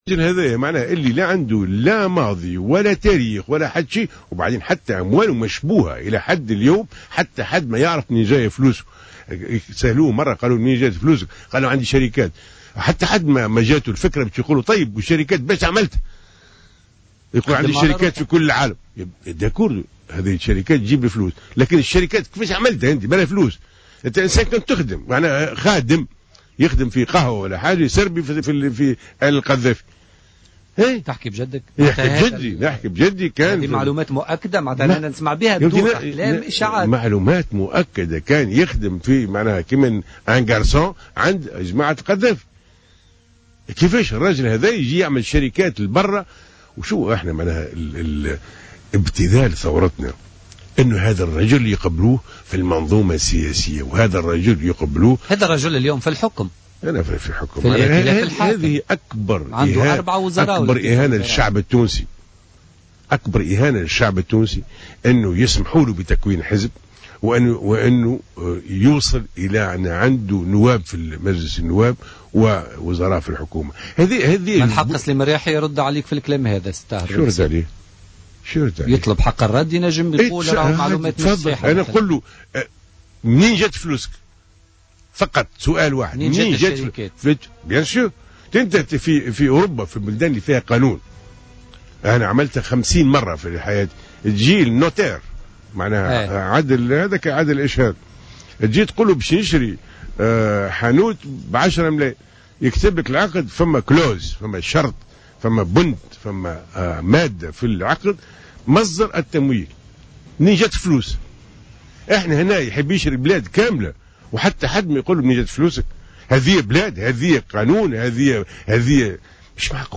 وقال بن حسين ضيف برنامج "بوليتيكا" إن تكوين الرياحي لحزب سياسي وحصول هذا الحزب على مقاعد في مجلس نواب الشعب يعدّ أكبر إهانة في حق الشعب التونسي، وفق تعبيره".